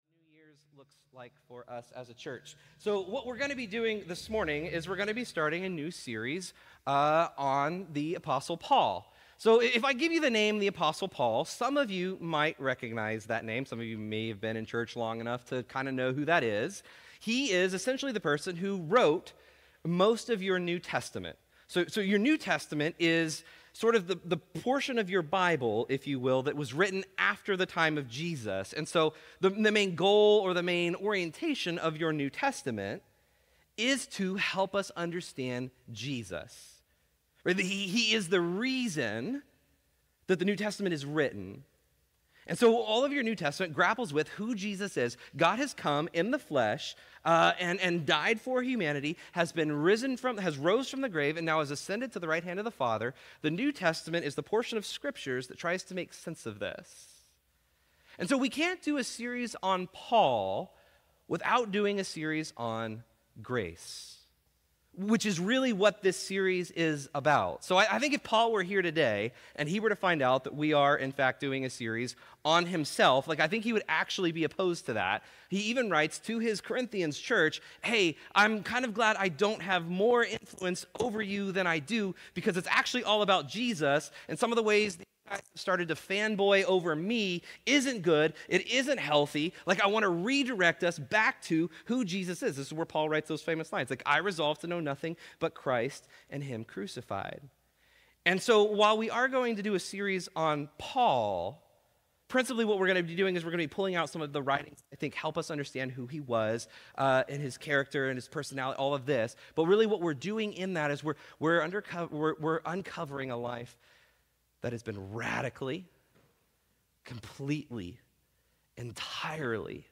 Restore Houston Church Sermons Does God Save Me Even When I Don’t Put My Faith in Him?